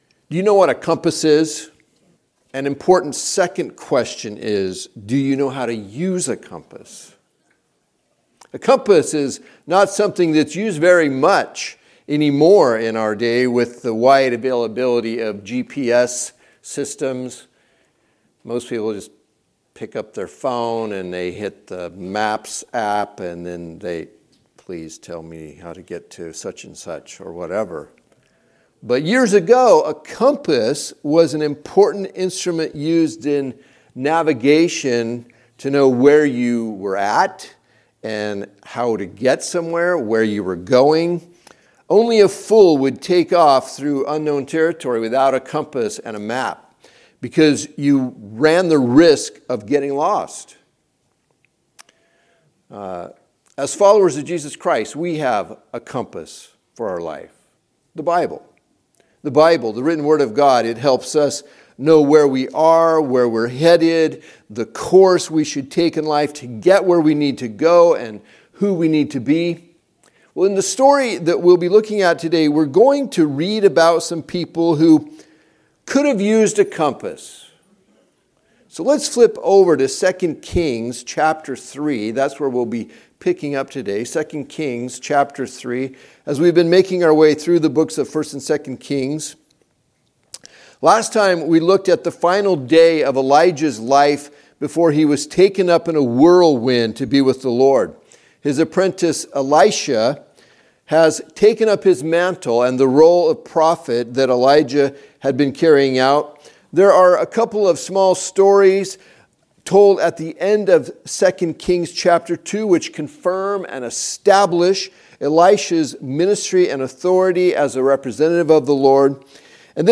Teaching from Sunday AM service